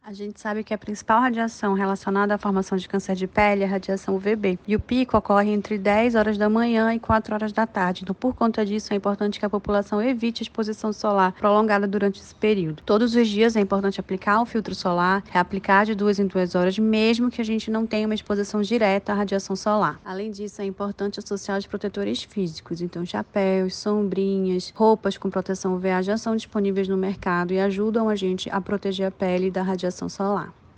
Alguns cuidados são primordiais como relata a dermatologista